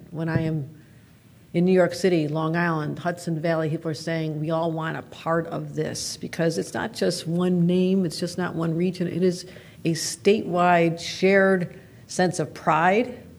Governor Katy Hochul was in Syracuse today to discuss Micron coming to the Syracuse area.